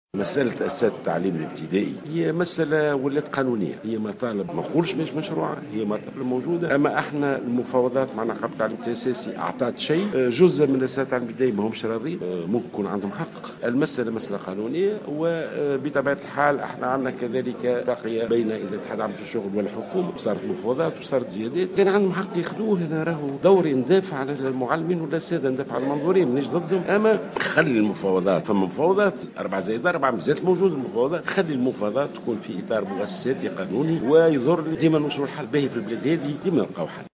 أكد وزير التربية ناجي جلول خلال حضوره افتتاح مهرجان المنستير الدولي مساء اليوم الأحد 17 جويلية 2016 في تعليق له على التحركات الاحتجاجية لأساتذة التعليم الابتدائي أن مطالبهم مشروعة وهي أصبحت اليوم مسألة قانونية .